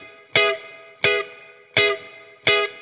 DD_StratChop_85-Emaj.wav